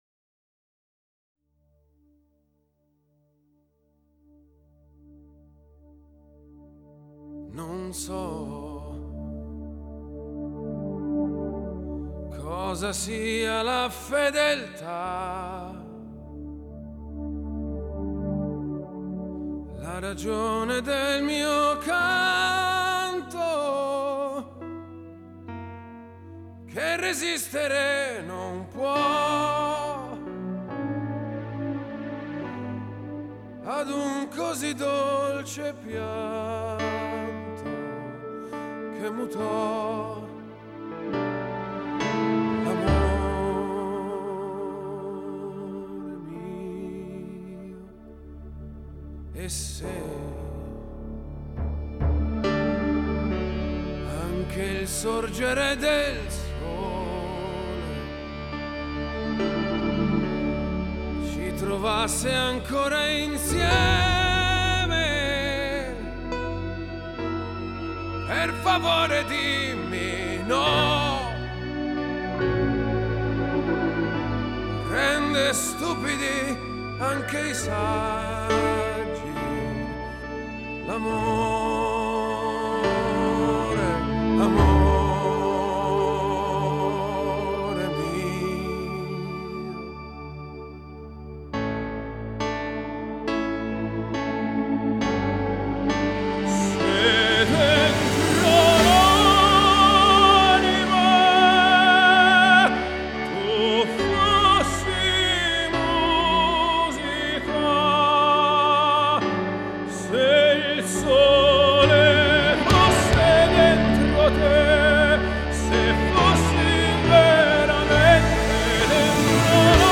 Classical Crossover, Pop, Opera